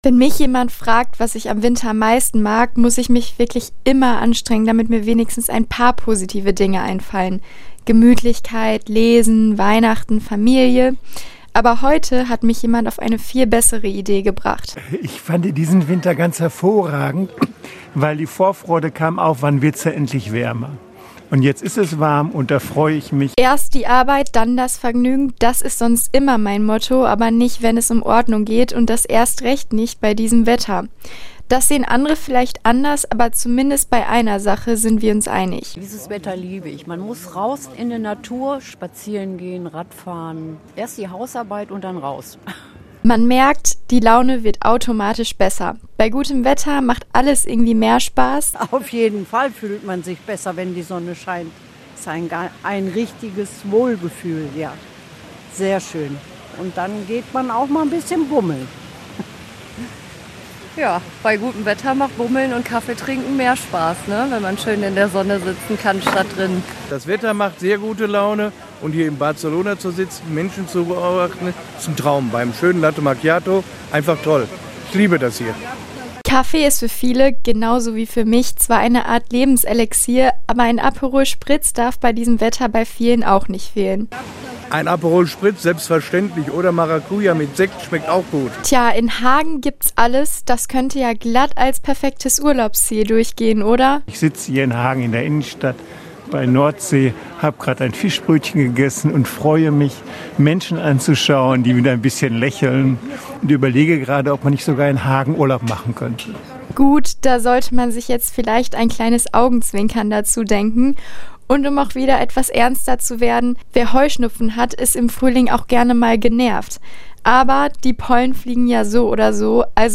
Wir haben uns mal umgehört, wie das Frühlingswetter unsere Stimmung beeinflusst und daraus einen kleinen Beitrag gemacht, den ihr euch hier anhören könnt: